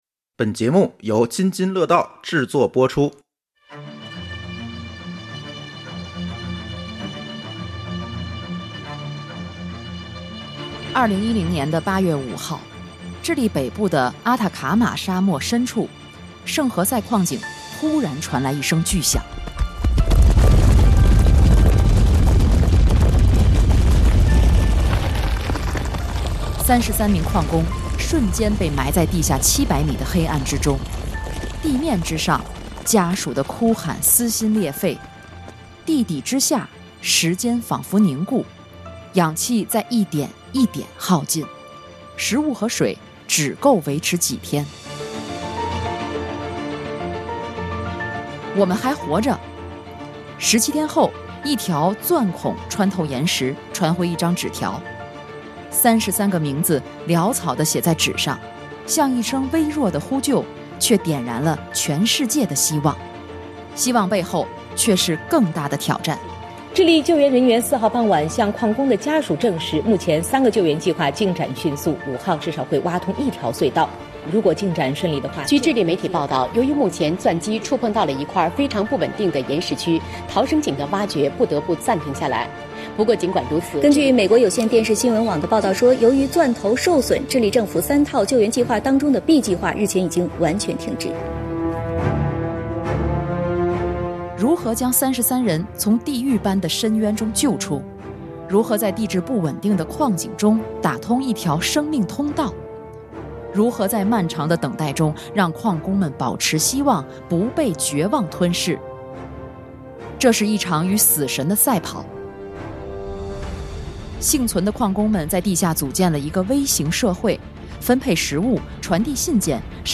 场地支持 / 声湃轩天津录音间